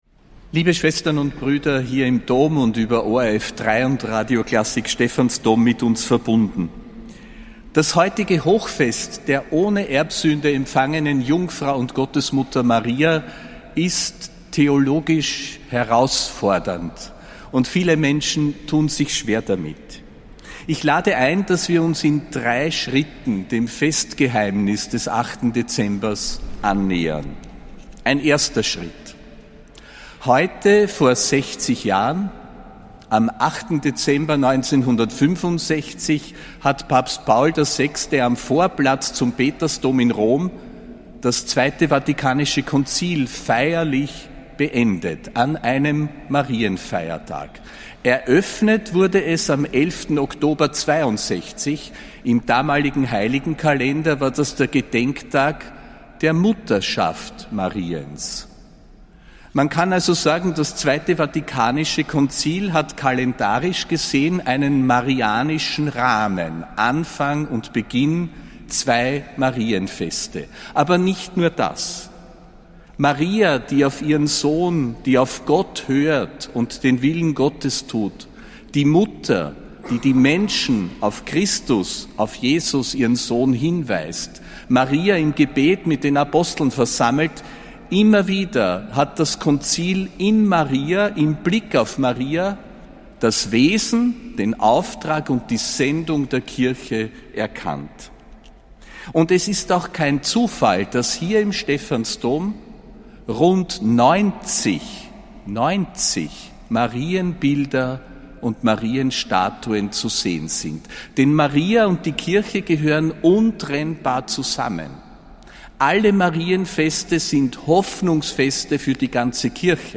Predigt von Josef Grünwidl zu Maria Empfängnis (8. Dezember 2025)